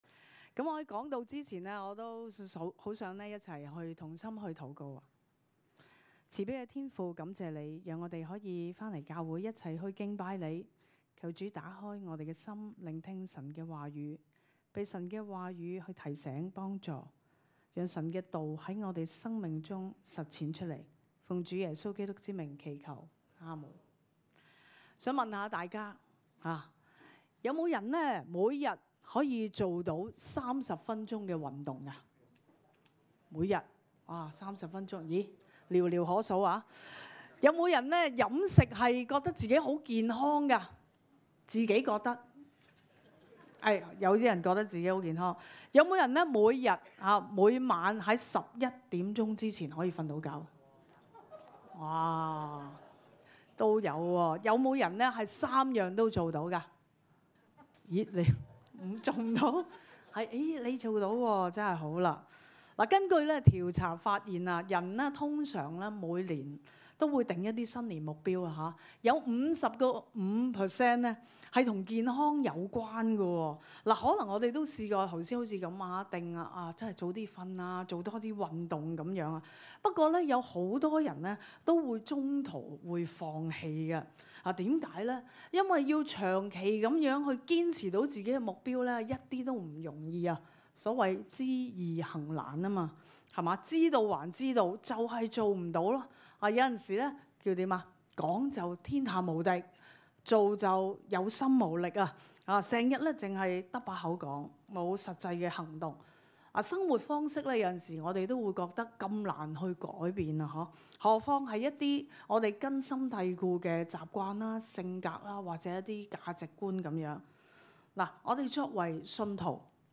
證道集